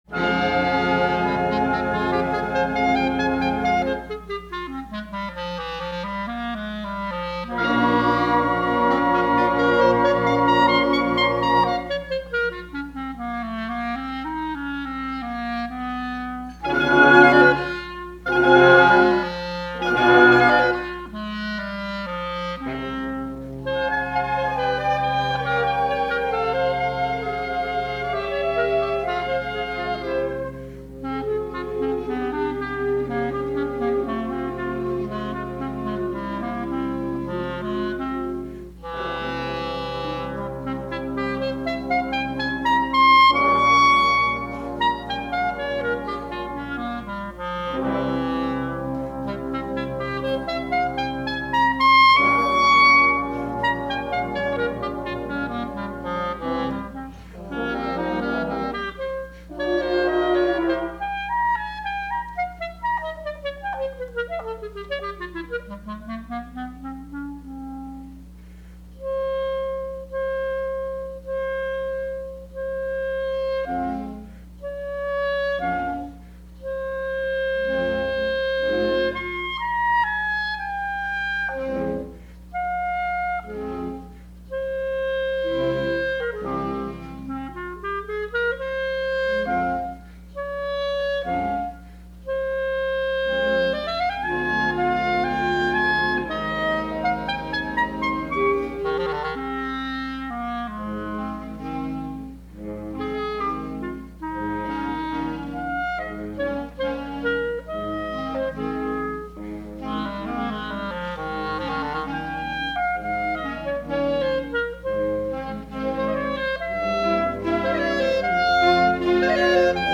Voicing: Bb Clarinet w/ Band